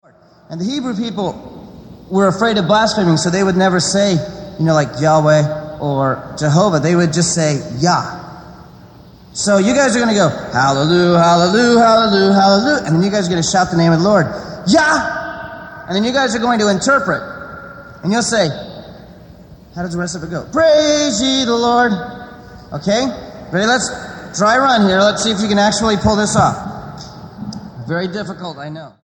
STYLE: Roots/Acoustic
Live